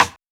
southside rim.wav